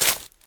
footsteps
decorative-grass-08.ogg